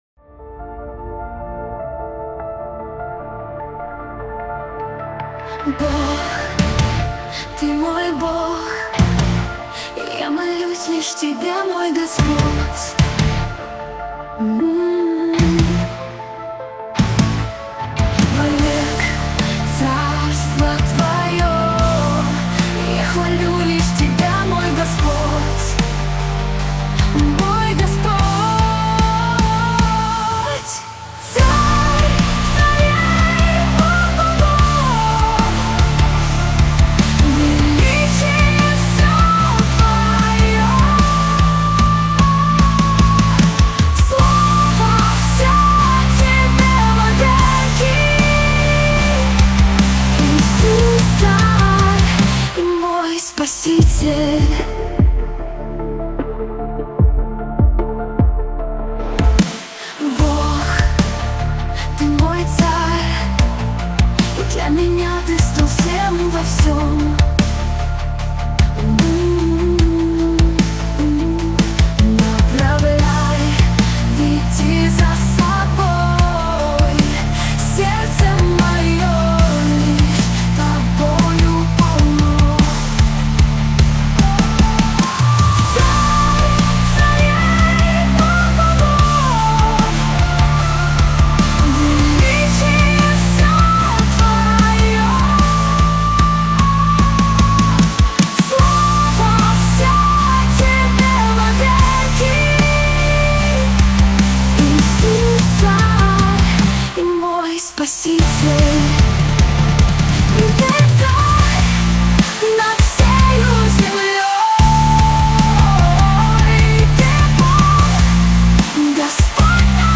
песня ai
218 просмотров 218 прослушиваний 18 скачиваний BPM: 77